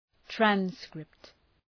Προφορά
{‘trænskrıpt}